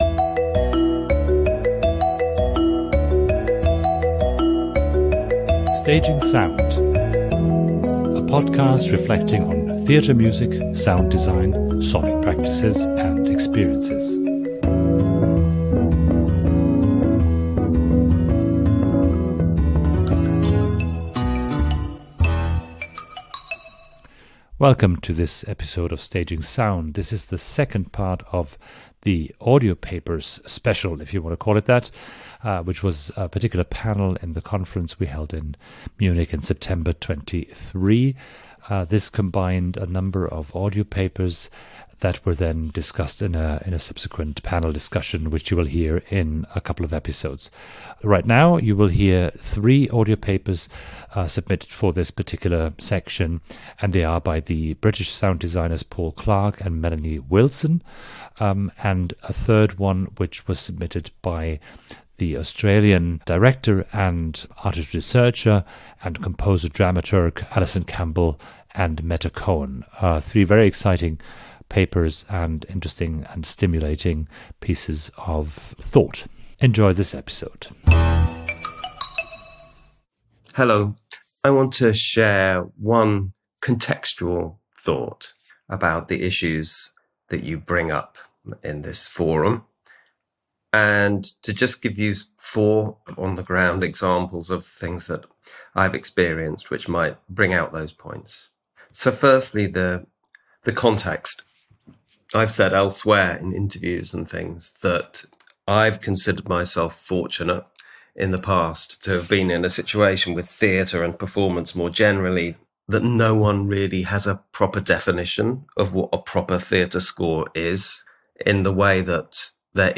Abstract In this audio essay